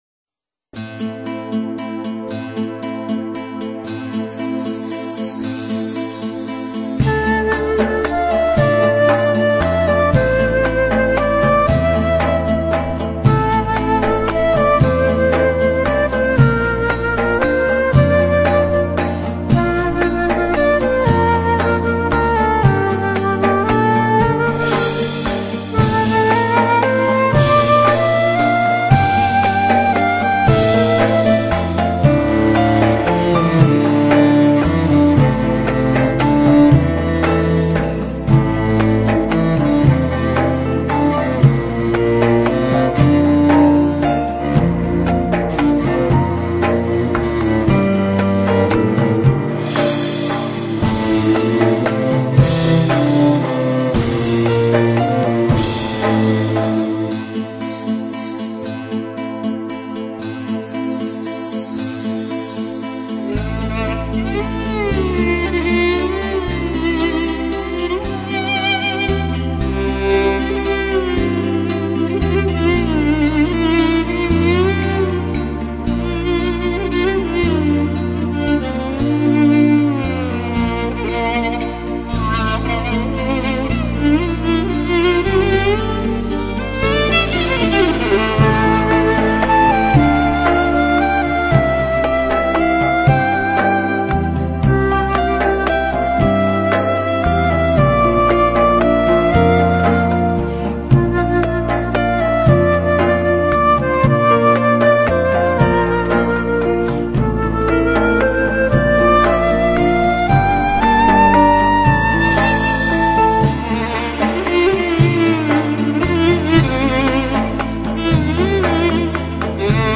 FON MÜZİK DUYGUSAL